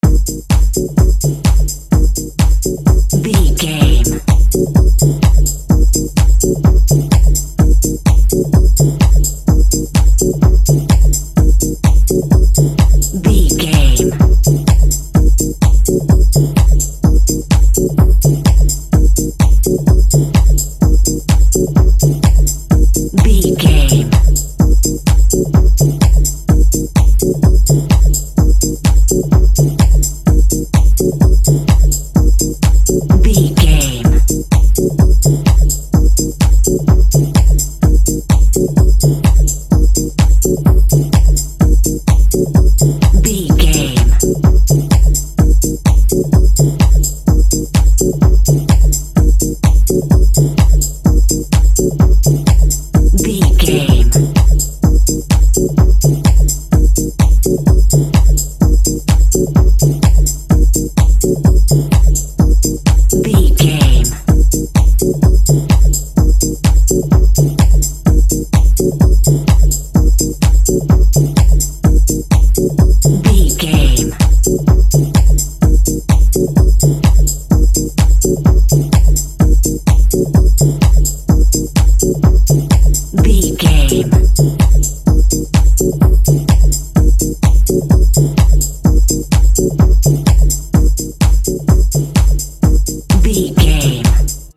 Fast paced
Aeolian/Minor
E♭
hard
intense
energetic
driving
repetitive
dark
synthesiser
drum machine
electro house
progressive house
synth lead
synth bass